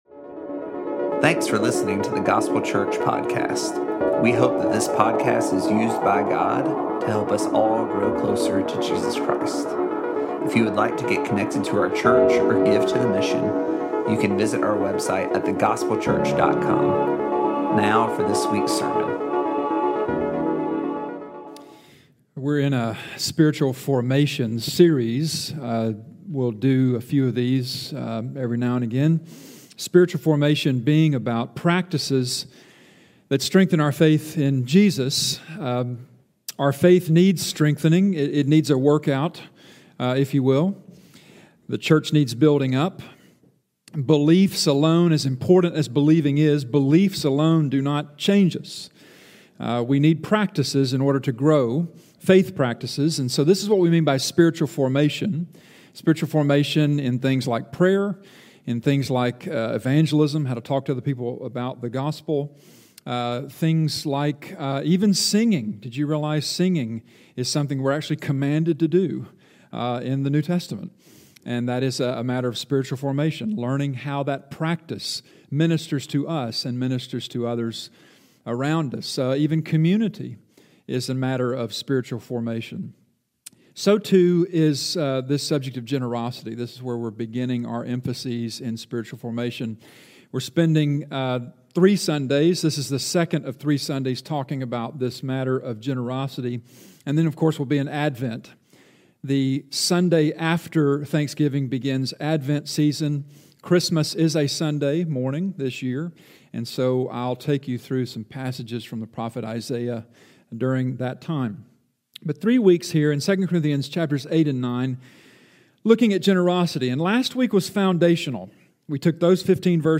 Utilizing passages from 2 Corinthians 8–9, this series focuses on principles that shape and carry our giving. This is the second sermon of three in this series.